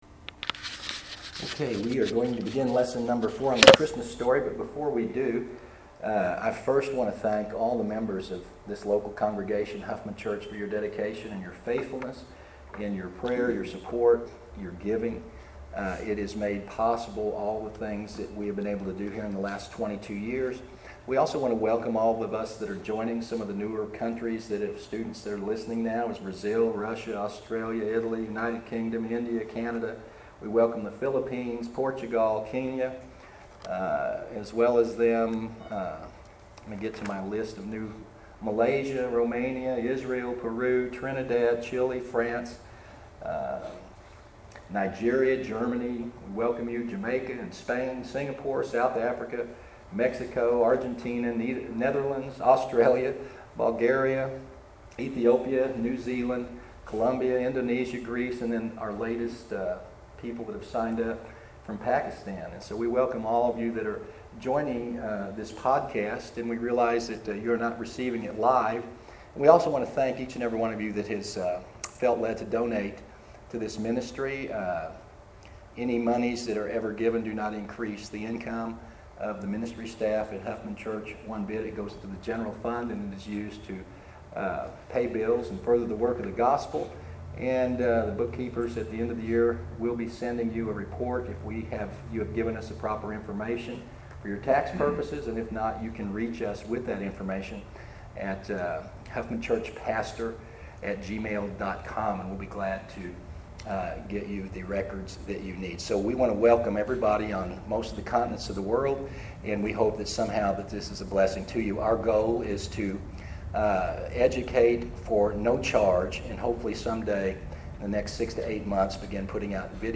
Birth of Christ: Lesson Five